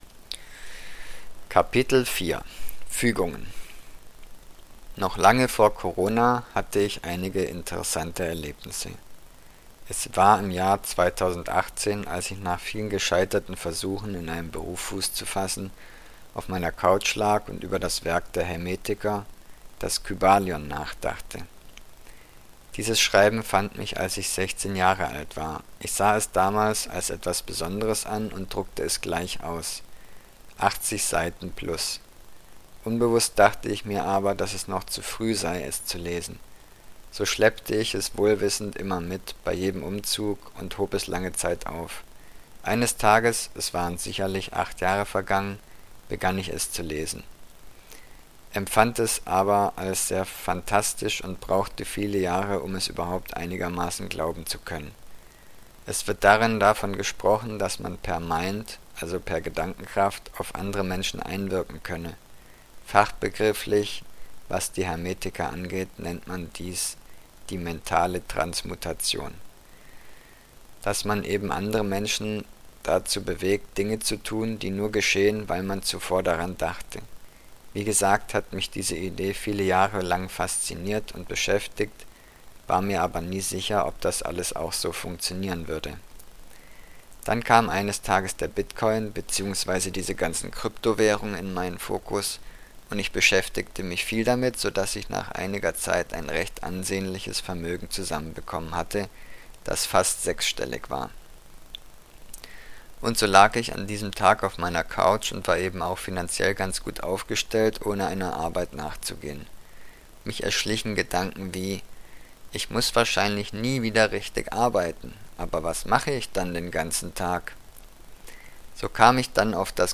Pink Probekapitel 1 als Hörbuchdatei